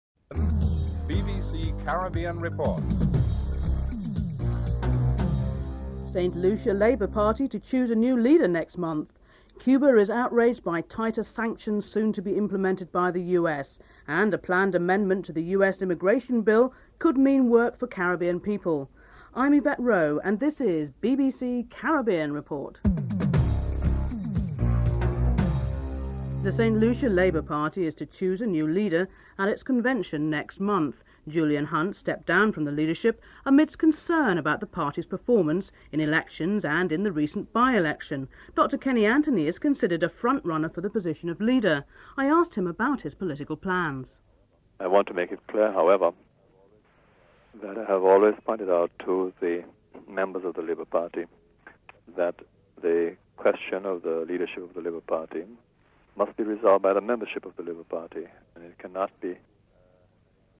Dr Kenny Anthony is interviewed (00:26-02:53)
Opposition Leader Rosie Douglas is interviewed (08:22-10:57)
Labour Peer Lord Clifford is interviewed (12:53-15:01)